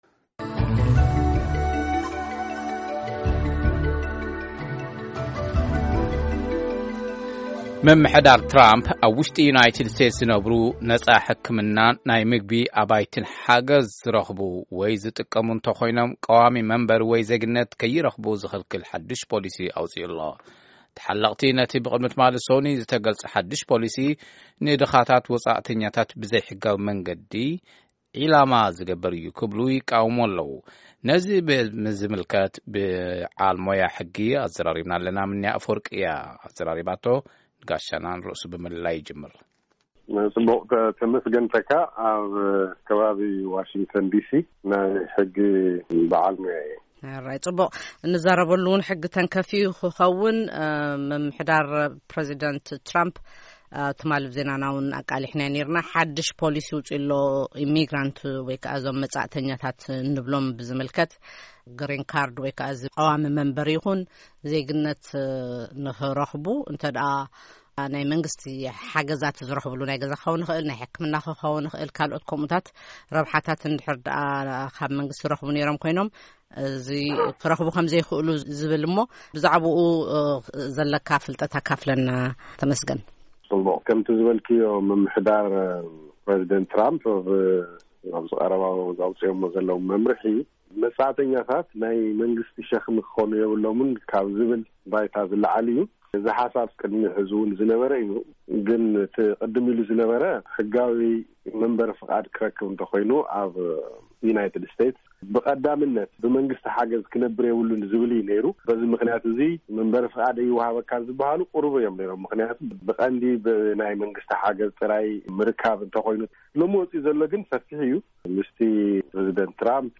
ቃለ መጠይቕ ምስ በዓል ሞያ ሕጊ ኣብ ጉዳይ ሓድሽ ዝወጸ ፖሊስ ኢምግሬሽን ኣሜሪካ